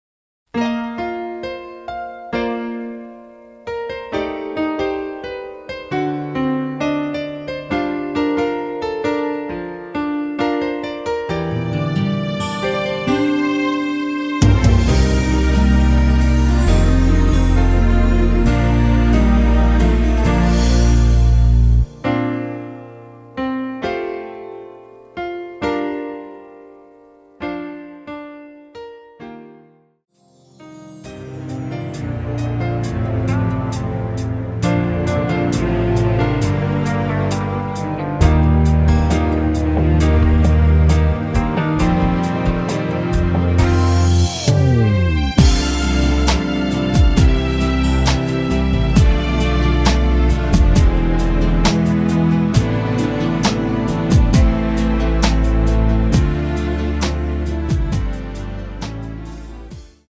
Bb
◈ 곡명 옆 (-1)은 반음 내림, (+1)은 반음 올림 입니다.
앞부분30초, 뒷부분30초씩 편집해서 올려 드리고 있습니다.
중간에 음이 끈어지고 다시 나오는 이유는